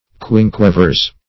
Search Result for " quinquevirs" : The Collaborative International Dictionary of English v.0.48: Quinquevir \Quin"que*vir\, n.; pl; E. Quinquevirs , L. Quinqueviri .